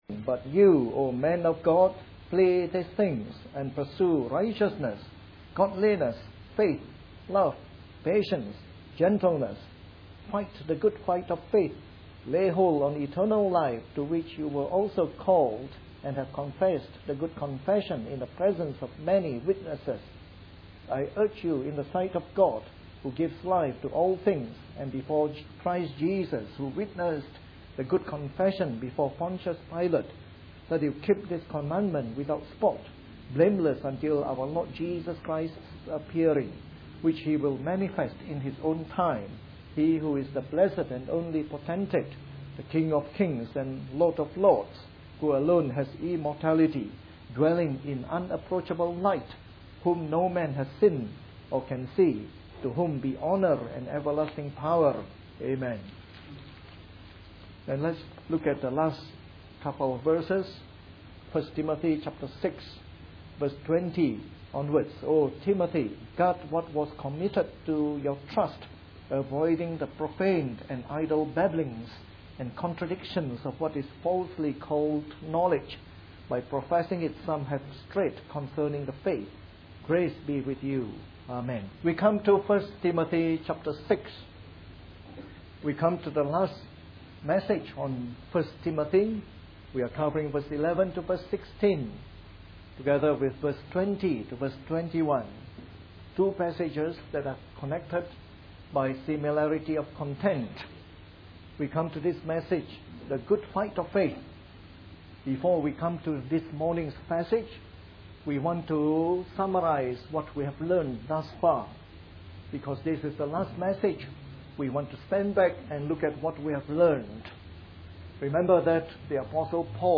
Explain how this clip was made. A sermon in the morning service from our series on 1 Timothy.